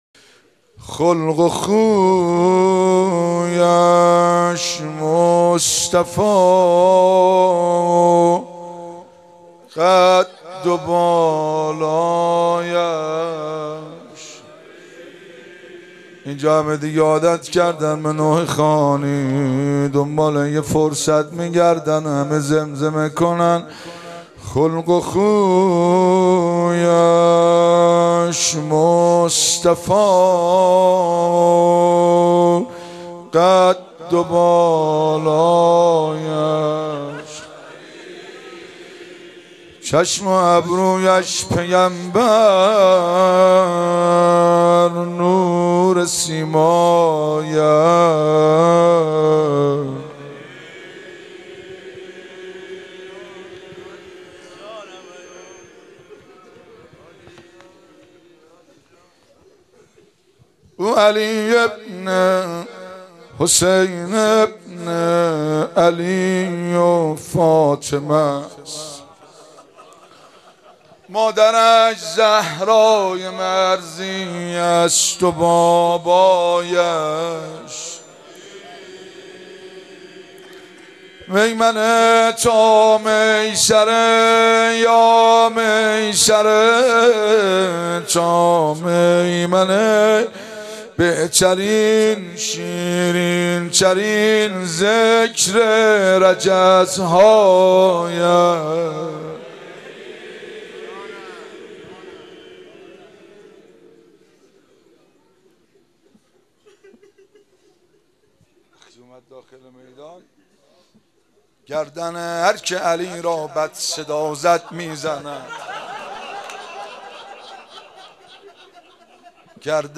روضه شب هشتم